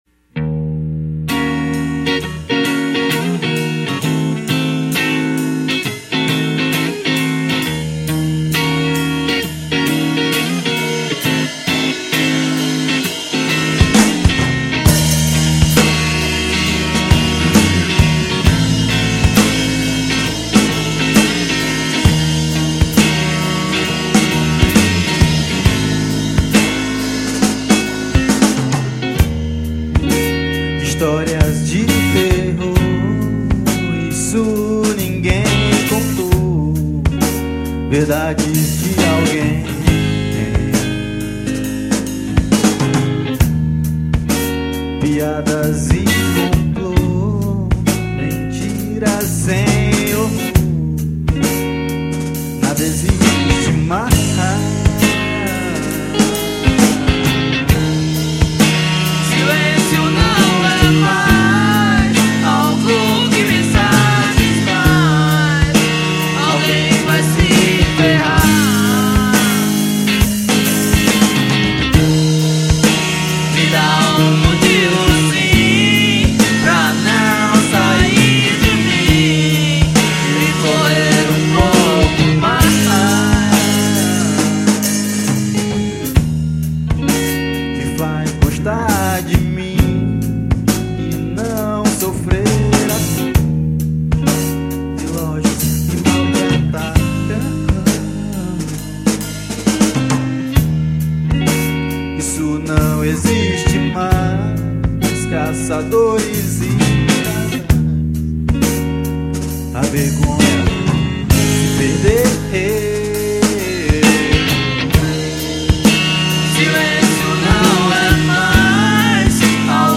1720   04:52:00   Faixa:     Rock Nacional